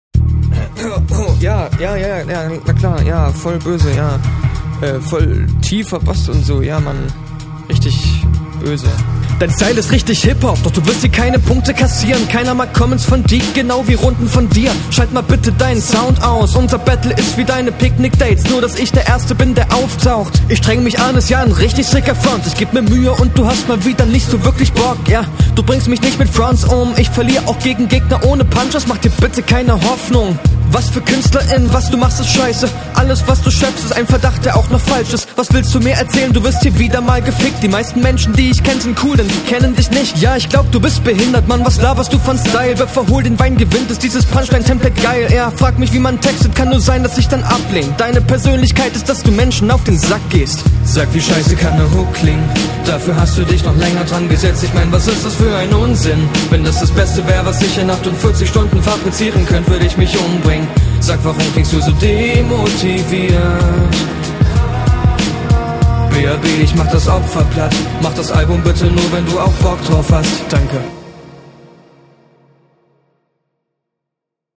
Was den Flow, Betonungen und Stimmeinsatz betrifft, ziehst du …
Vibe ist sehr cool, mische passt, ich würde die stimme n bissl leiser machen, damit …